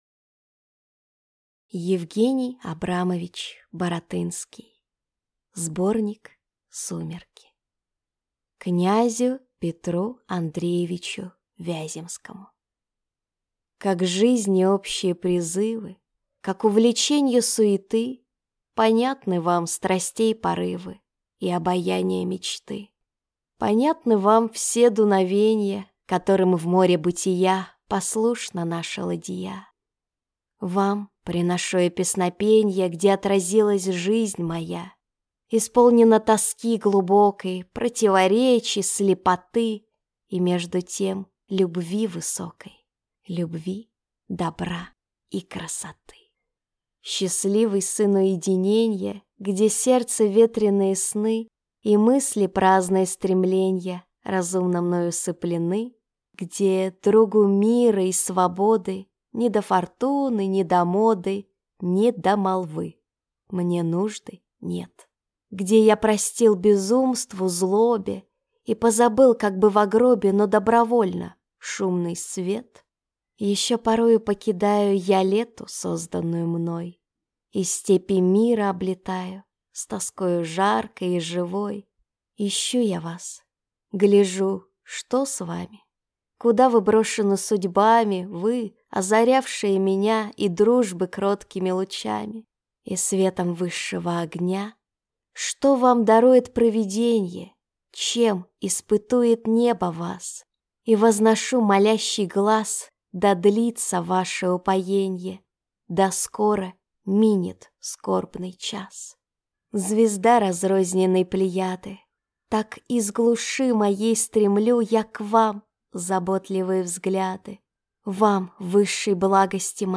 Аудиокнига Сумерки | Библиотека аудиокниг